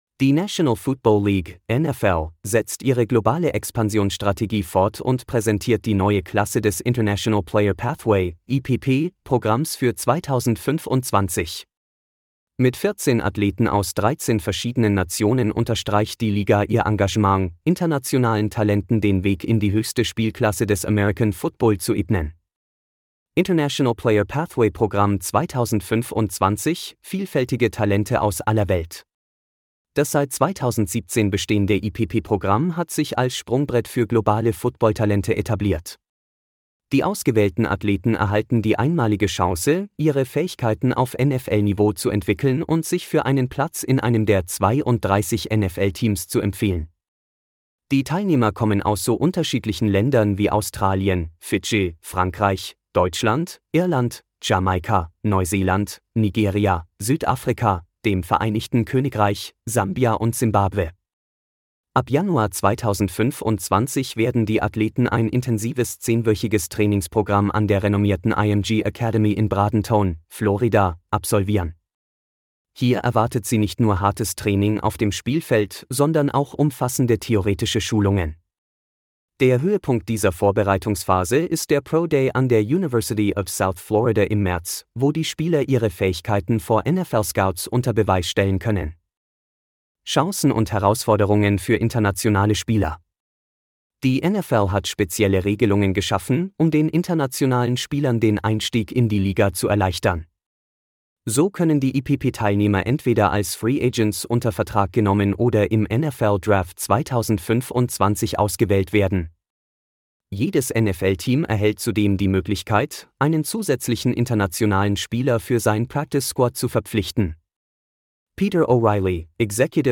Diese Audioversion des Artikels wurde künstlich erzeugt und wird stetig weiterentwickelt.